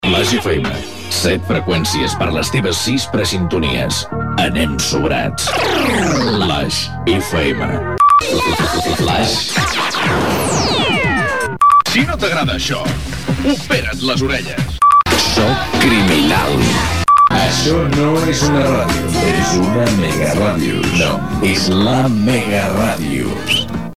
Indicatius de l'emissora